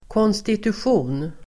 Uttal: [kånstitusj'o:n]